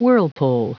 Prononciation du mot whirlpool en anglais (fichier audio)